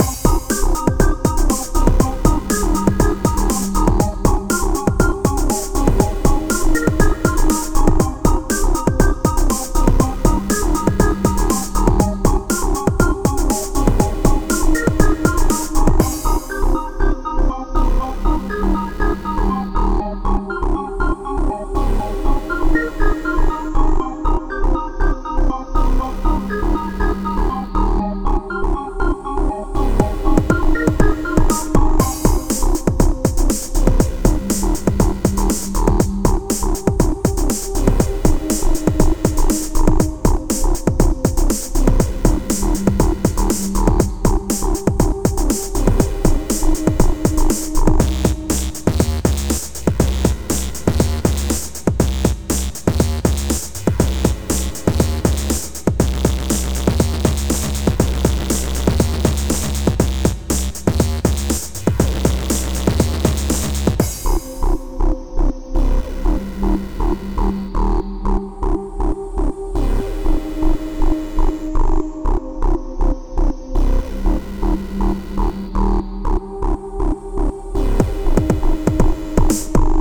A little looping song I made for background music.